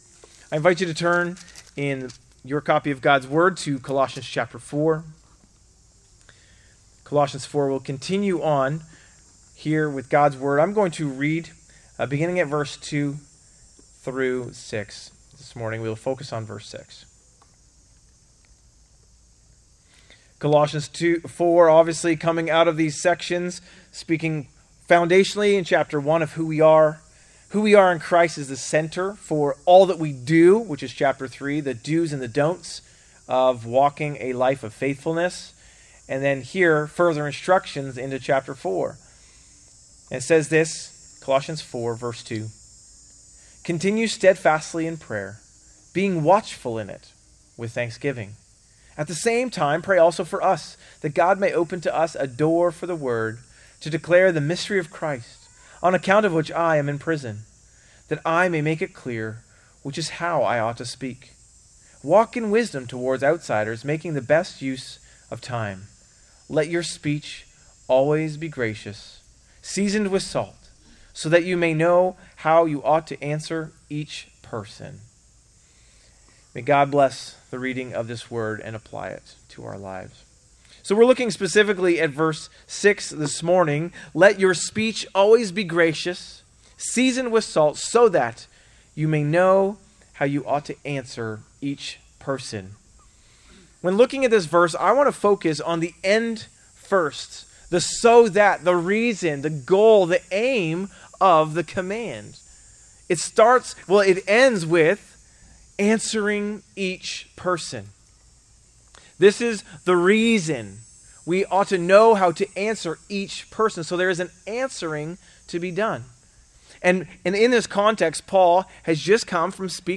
A message from the series "In Christ Alone."